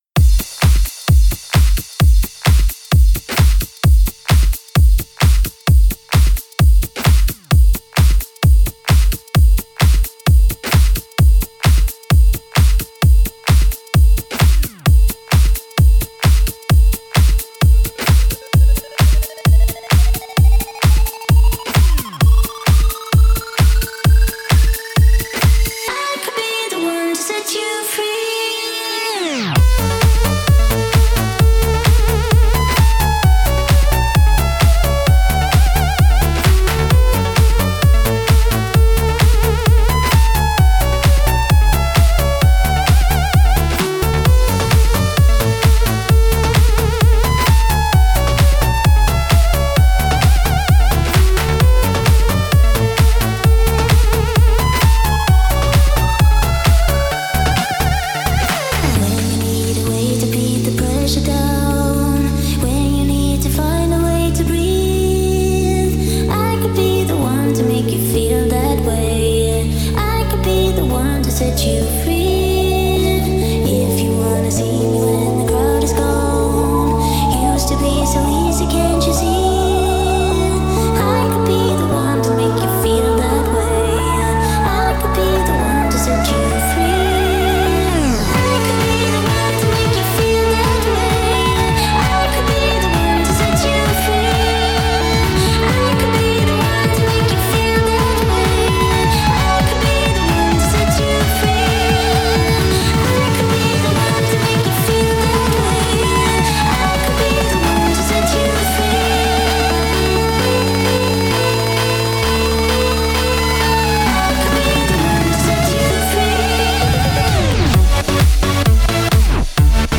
Sesión para fiesta privada
Revive el ritmo latino con estilo house